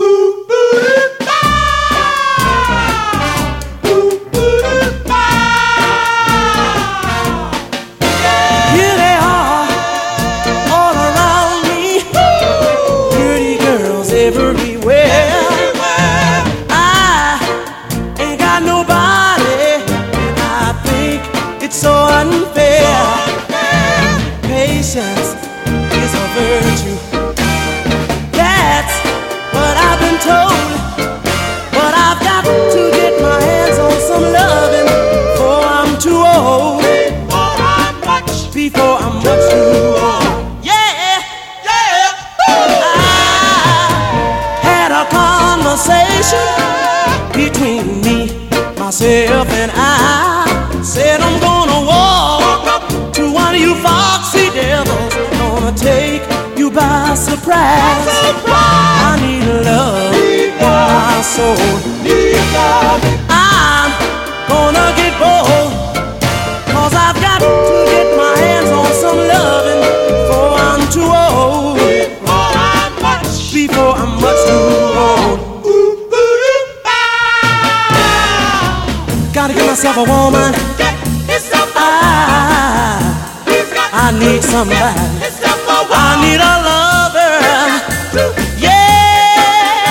60年代前半のゴージャスでコクのあるアーリー・ソウルから、ダンサブルなノーザン・ソウルまでも収録！